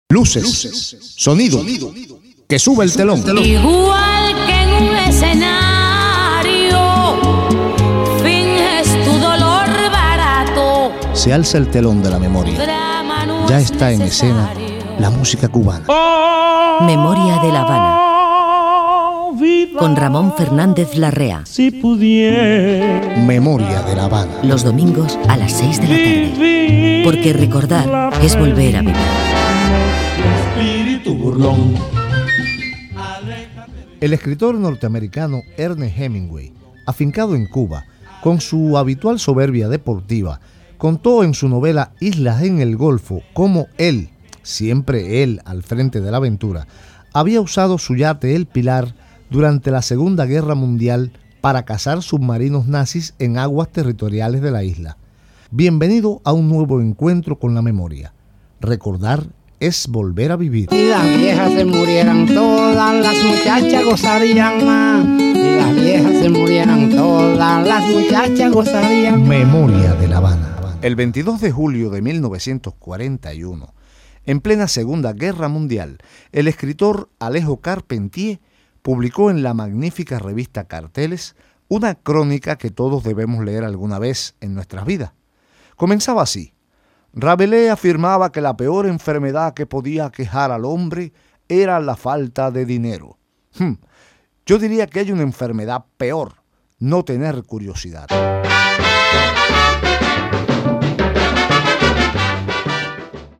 Careta del programa, records de fets relacionats amb els escriptors Ernest Hemingway i Alejo Carpentier